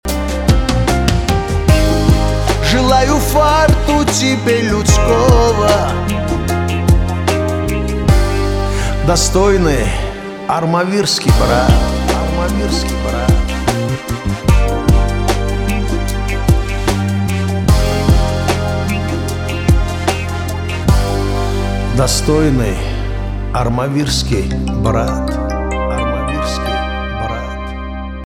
кавказские
битовые , труба